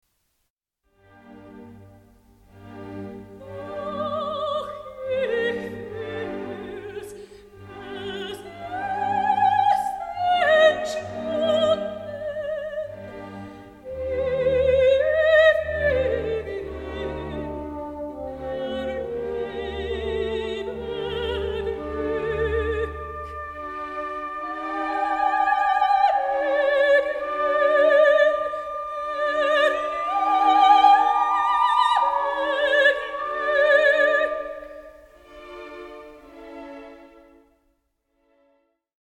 Lost love, rejection, despair
deeply moving aria
key g